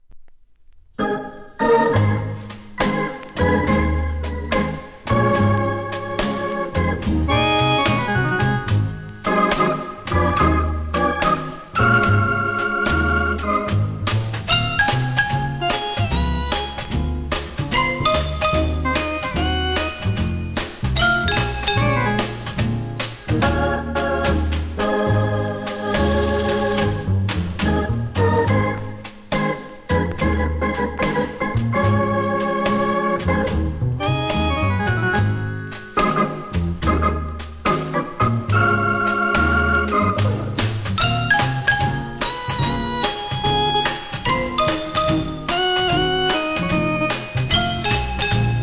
店舗 ただいま品切れ中です お気に入りに追加 オルガンJAZZ INST.!!